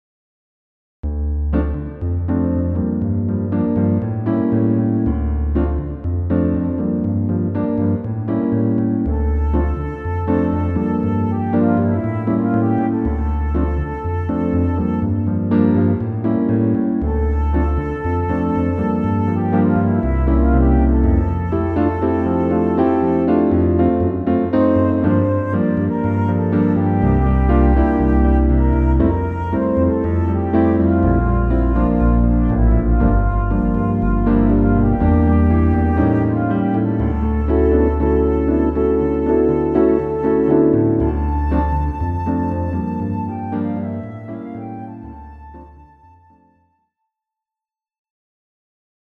A very cool Bossanova
Key: D minor